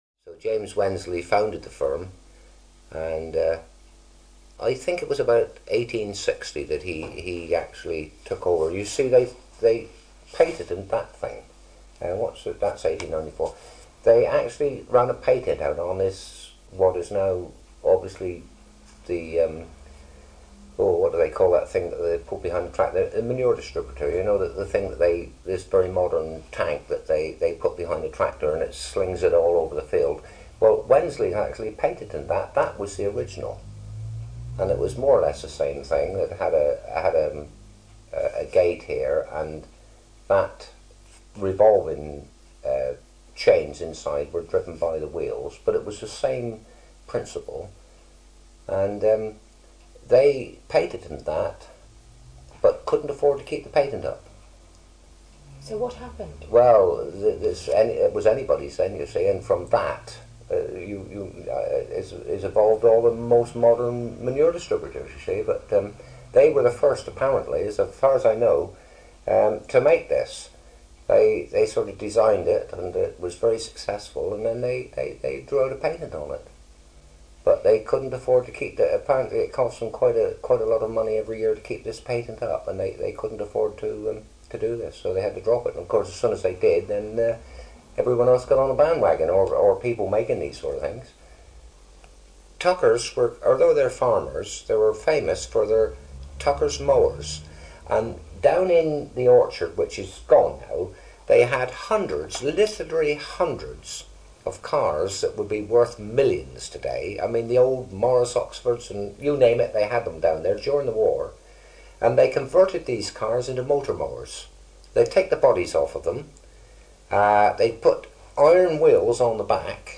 This recording was made in 1989 as part of the research for an exhibition at the Somerset Rural Life Museum entitled ‘Made in Somerset’.
For access to full interview please contact the Somerset Heritage Centre.